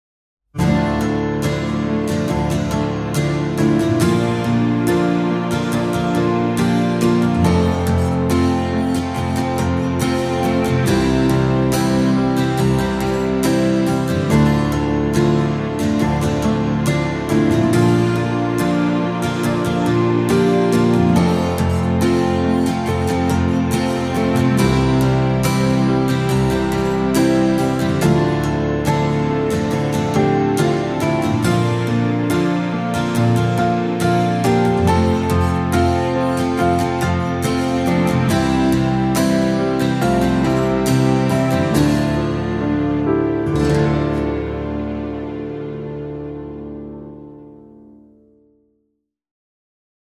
intimiste - folk - melodieux - romantique - aerien